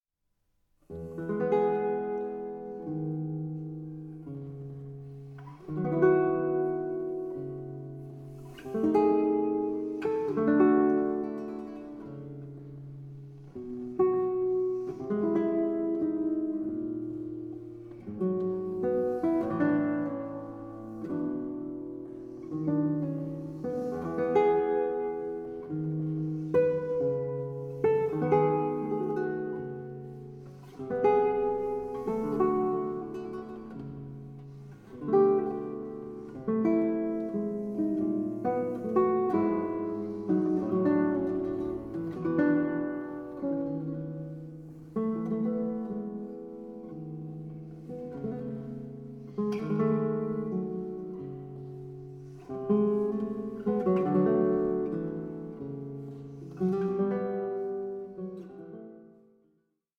SOLO GUITAR MASTERPIECE
Guitarist
chaconne-like finale